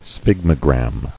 [sphyg·mo·gram]